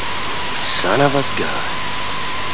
Amiga 8-bit Sampled Voice